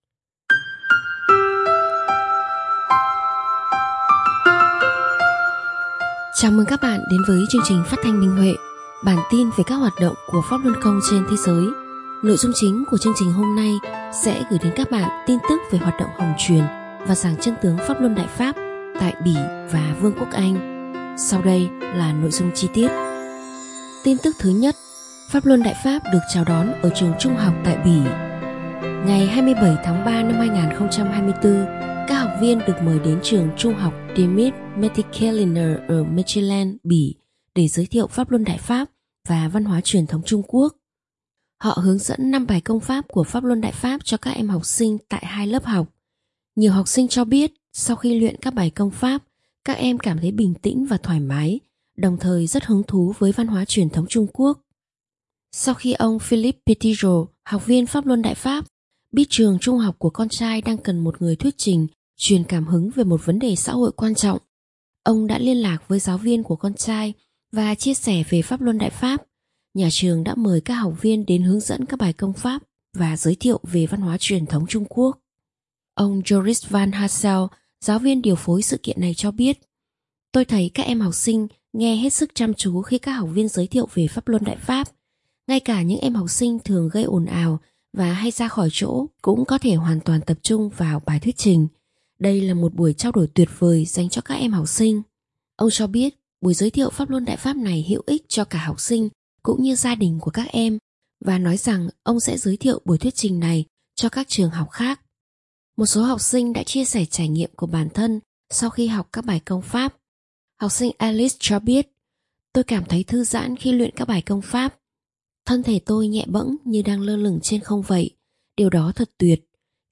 Chương trình phát thanh số 148: Tin tức Pháp Luân Đại Pháp trên thế giới – Ngày 03/04/2024